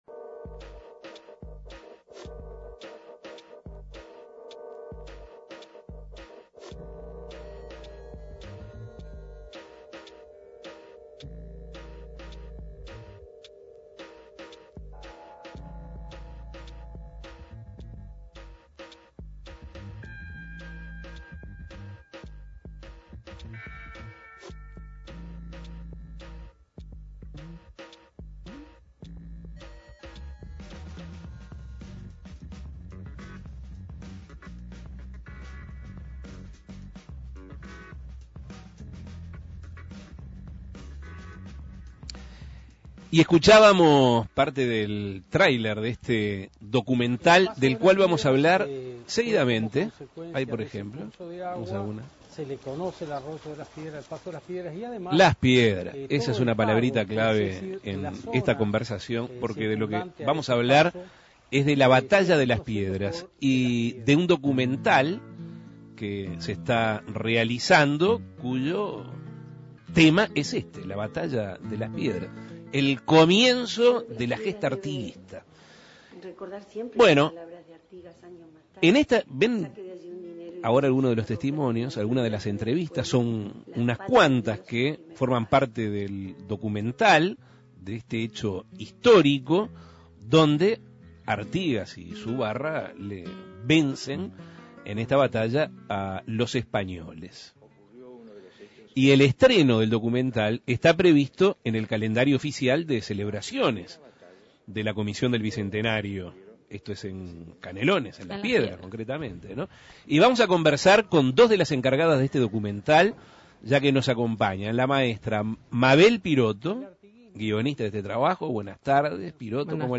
En el marco de los festejos del Bicentenario de la gesta artiguista, la Comisión de Patrimonio de Las Piedras está realizando un documental, cuyo centro temático es La Batalla de Las Piedras. Dos encargadas del filme dialogaron en Asuntos Pendientes.
Entrevistas